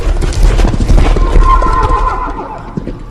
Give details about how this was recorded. Boosted sound for headless horseman. 2025-02-11 19:02:51 -05:00 28 KiB (Stored with Git LFS) Raw History